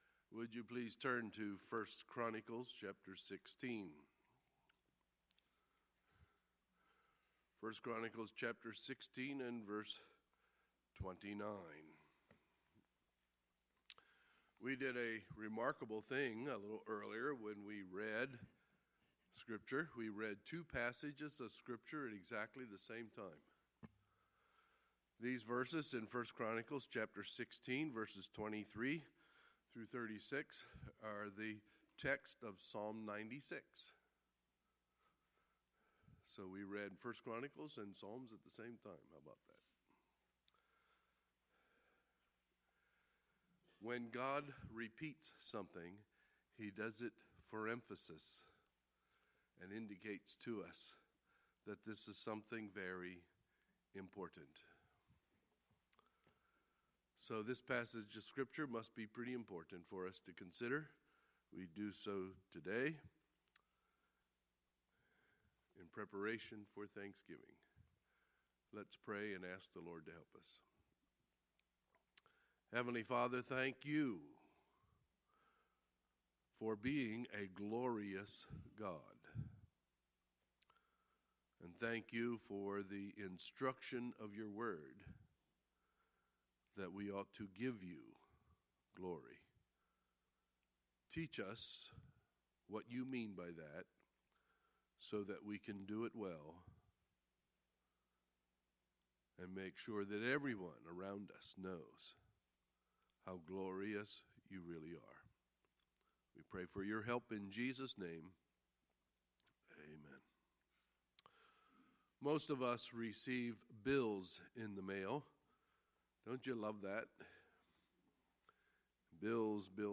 Sunday, November 20, 2016 – Sunday Morning Service